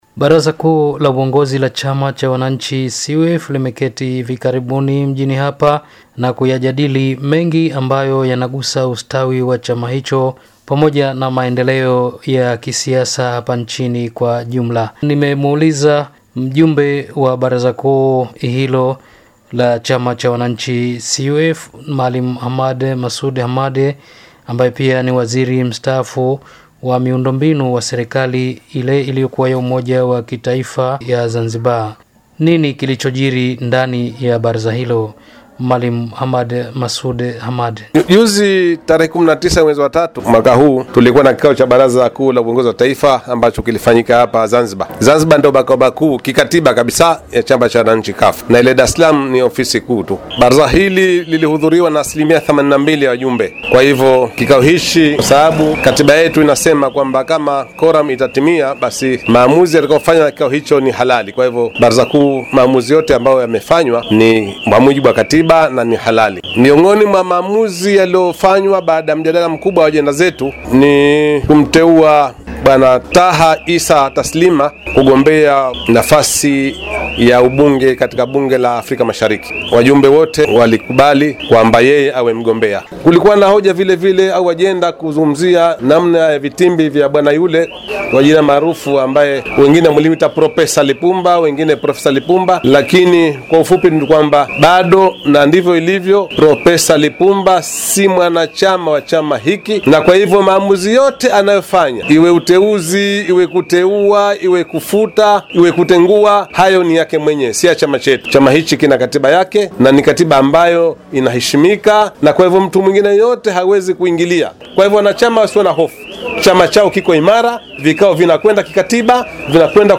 Mjumbe wa Baraza la Uongozi wa CUF azungumza na mwandishi wa Radio Tehran, Zainzibar